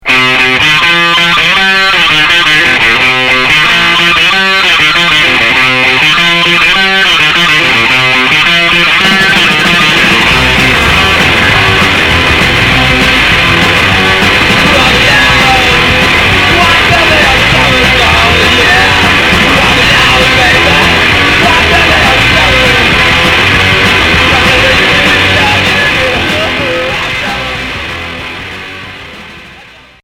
Rock noisy